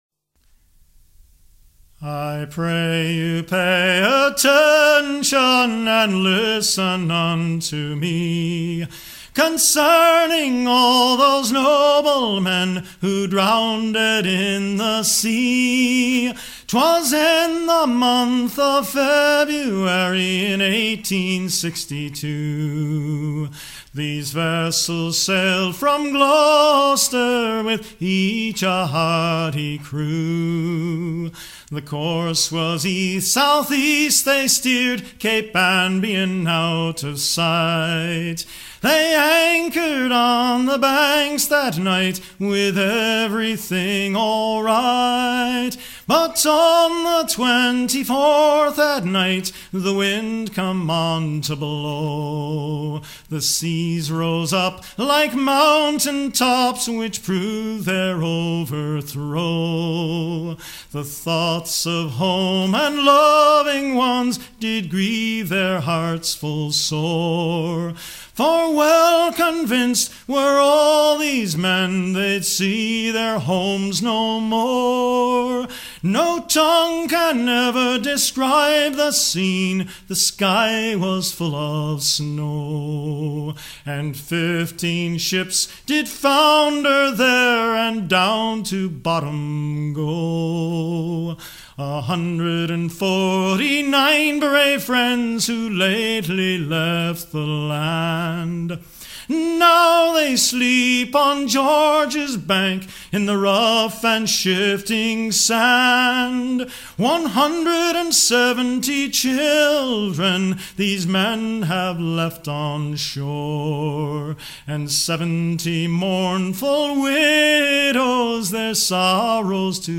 circonstance : maritimes ;
Pièce musicale éditée